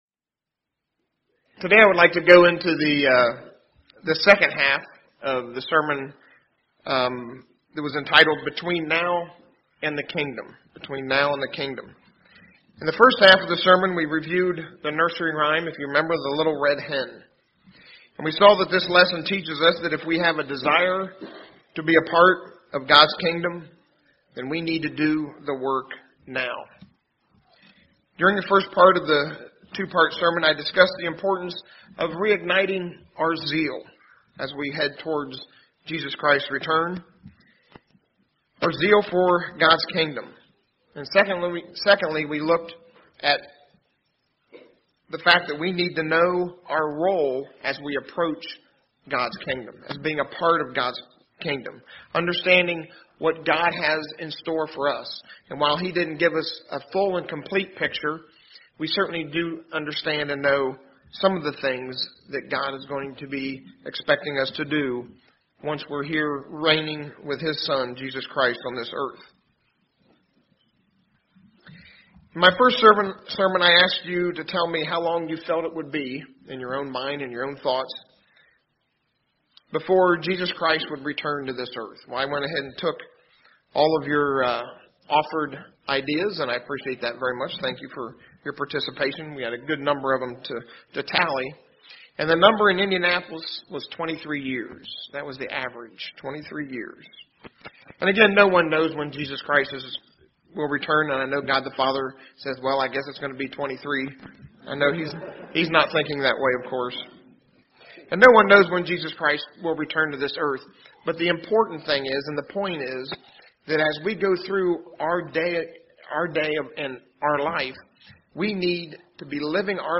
Sermons
Given in Indianapolis, IN Ft. Wayne, IN